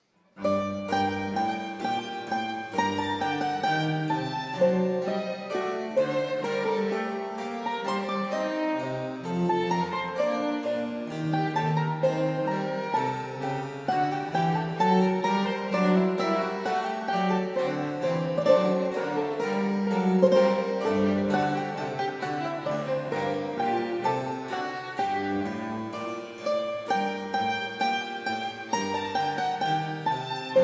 per violino – liuto – basso continuo